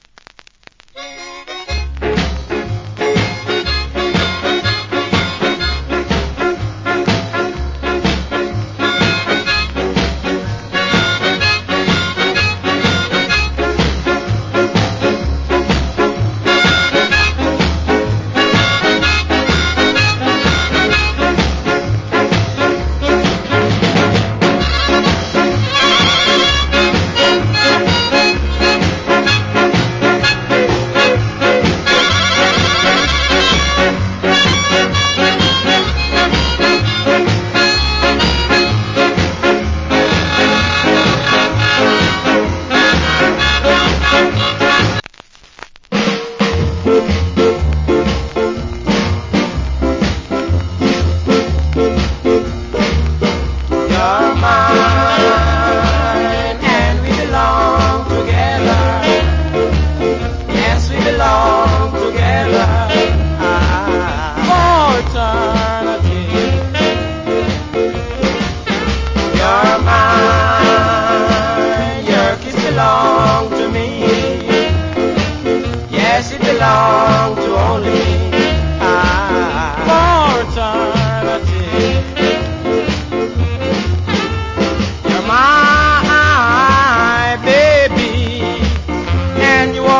Nice Ska Inst.